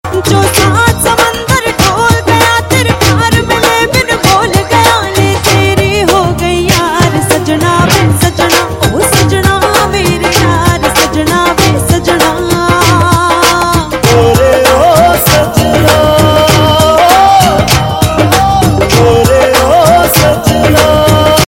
Navratri Ringtones